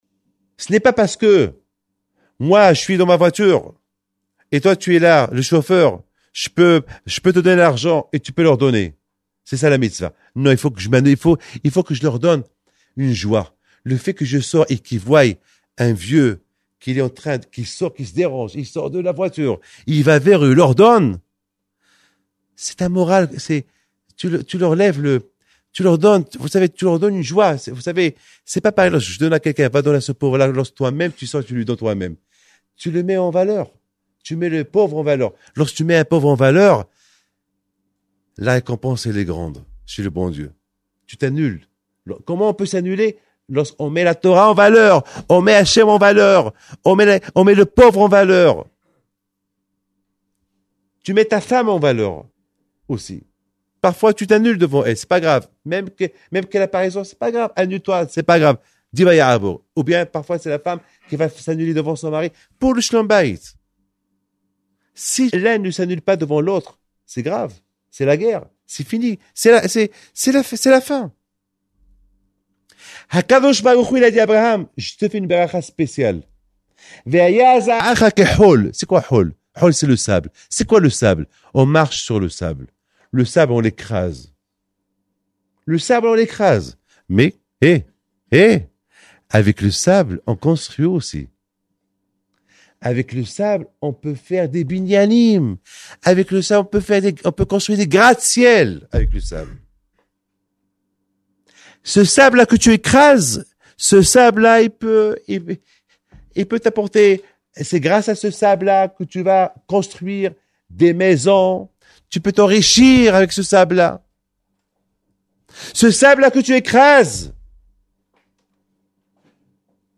01:21:00 DVAR TORAH est très heureux de vous convier à écouter ce Shiour donné à la Yeshiva à Villeurbanne